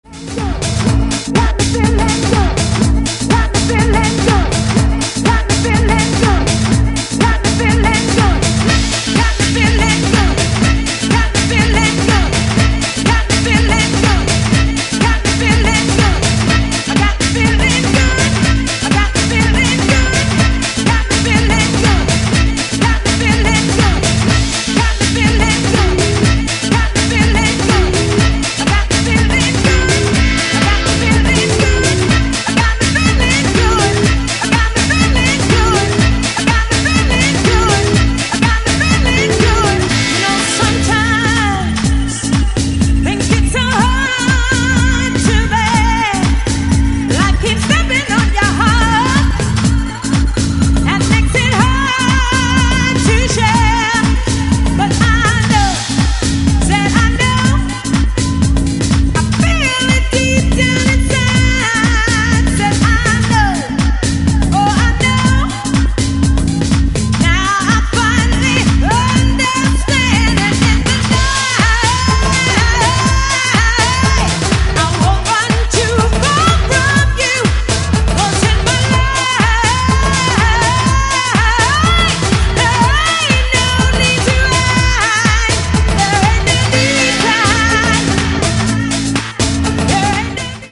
a dose of vocal house at it’s finest